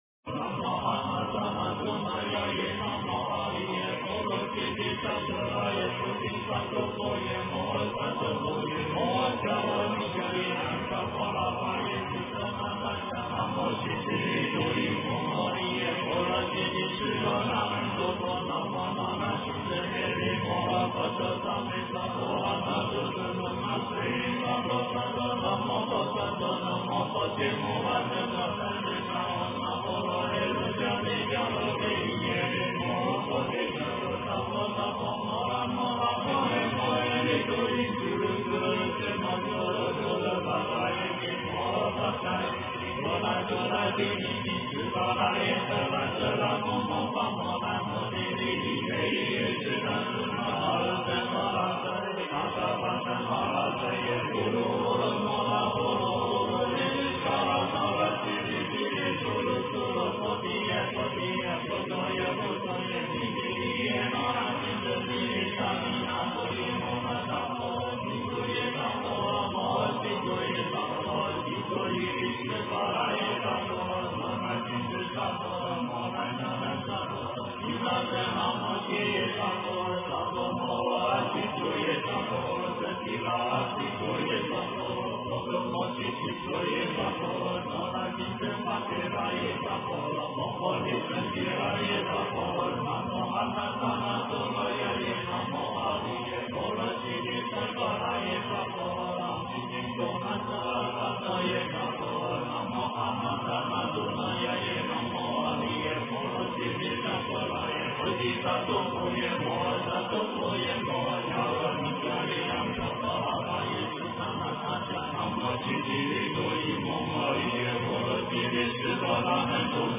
佛音 诵经 佛教音乐 返回列表 上一篇： 心经 下一篇： 大悲咒 相关文章 消业障六道金刚咒(国语演唱版